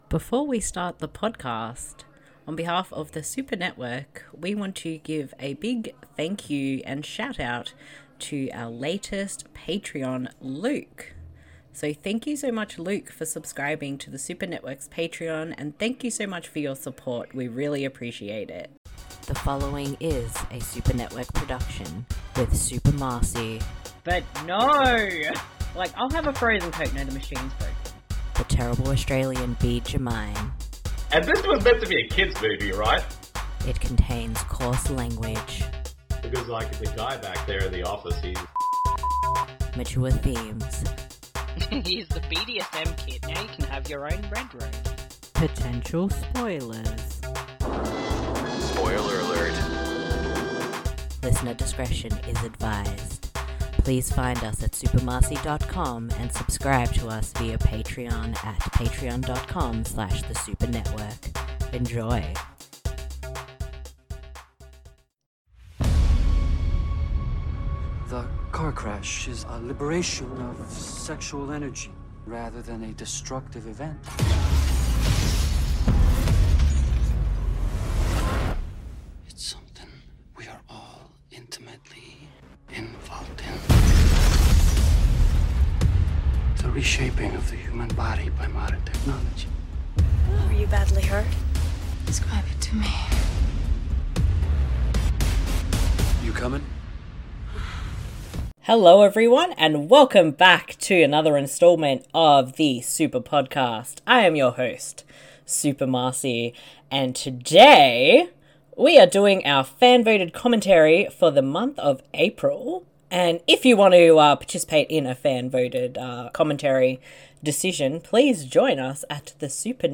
You simply need to grab a copy of the film, and sync up the podcast audio with the film.